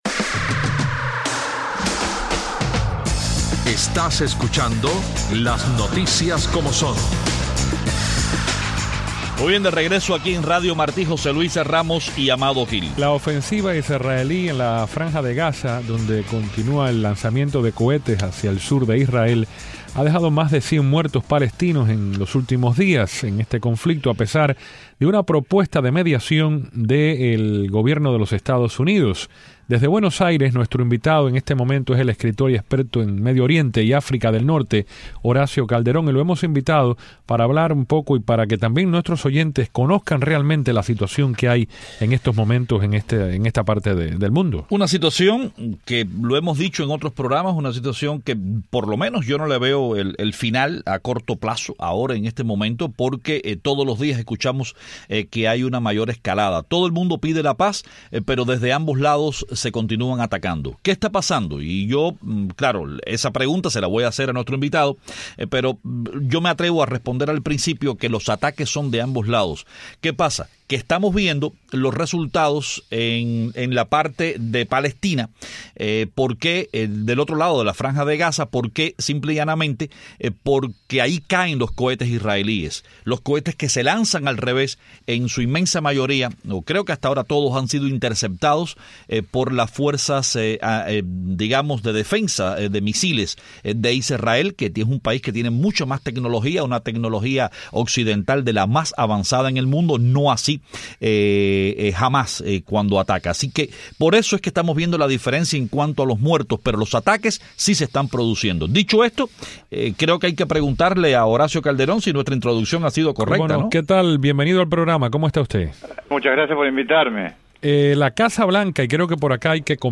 La ofensiva israelí en la franja de Gaza, donde continuaba el lanzamiento de cohetes hacia el sur de Israel, dejó por lo menos 100 muertos palestinos en cuatro días en este conflicto, a pesar de una propuesta de mediación de Washington. Desde Buenos Aires, nuestro invitado es el escritor y experto en Medio Oriente y África del norte